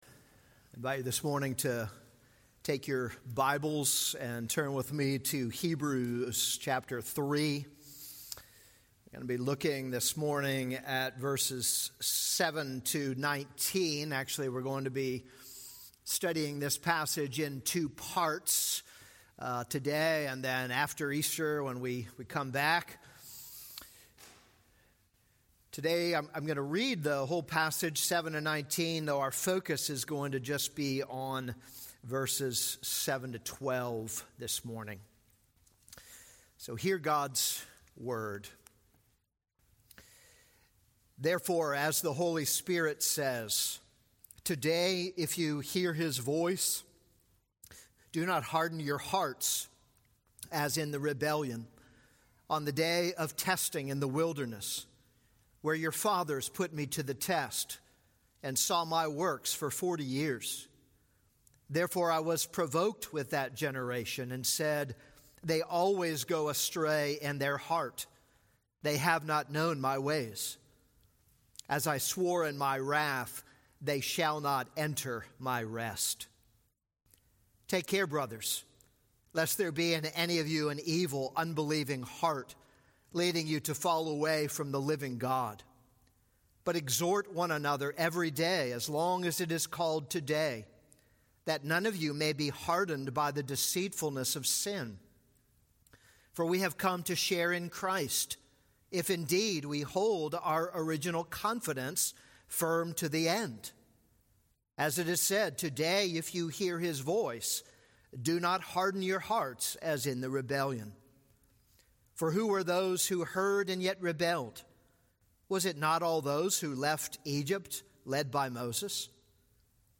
This is a sermon on Hebrews 3:7-19.